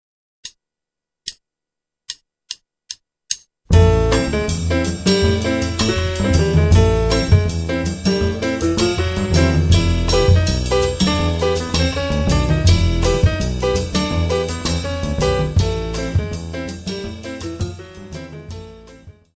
Bass
Drums